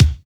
STRONG KICK.wav